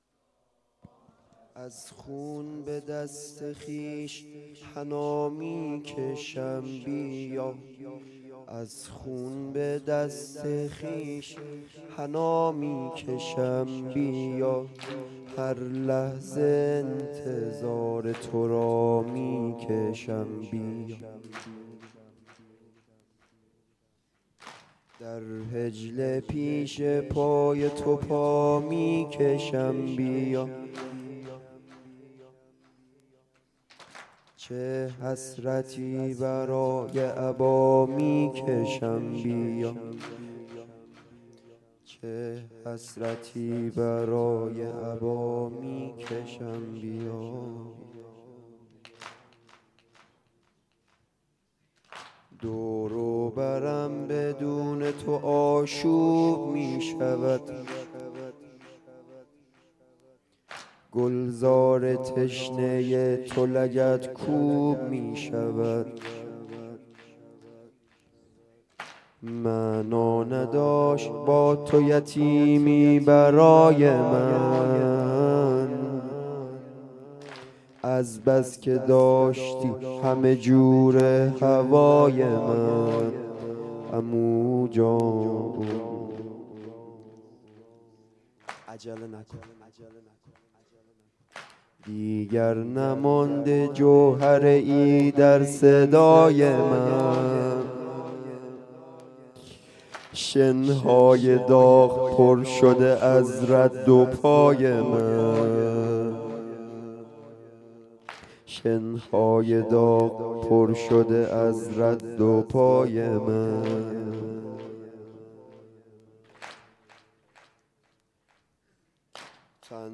شب ششم محرم